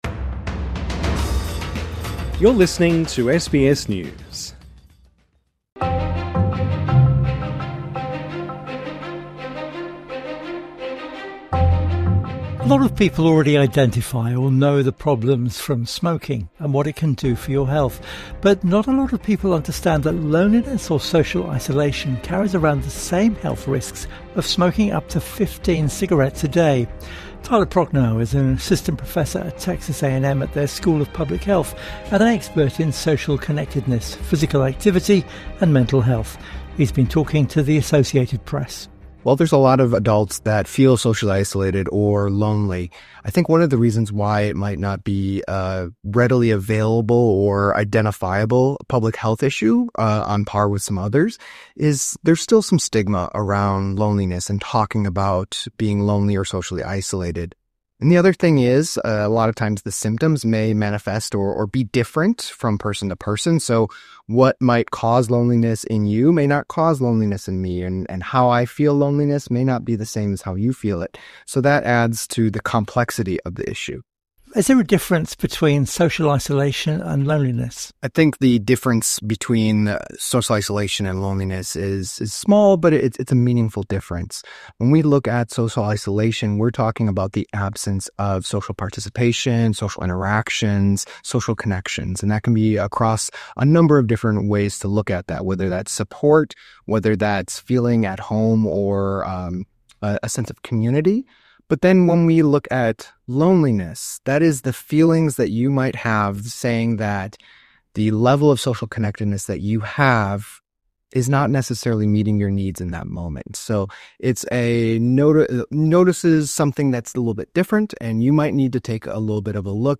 INTERVIEW: Loneliness - the hidden health danger